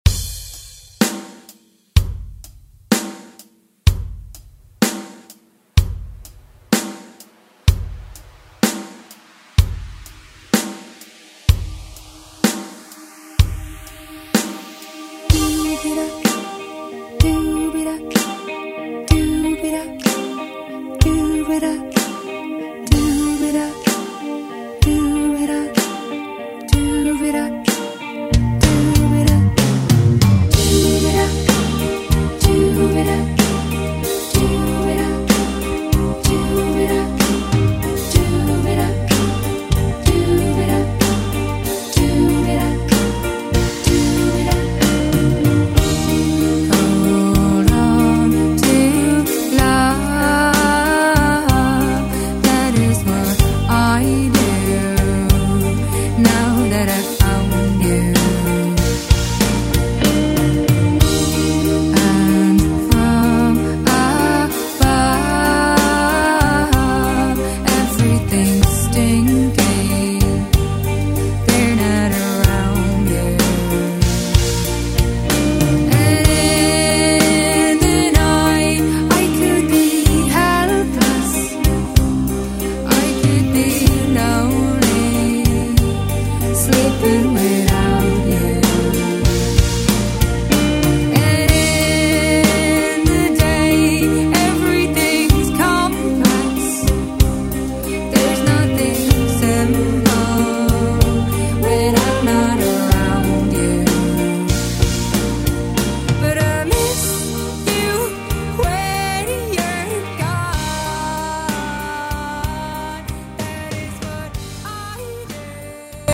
Alternative Rock Music Extended ReDrum Clean 63 bpm
BPM: 63 Time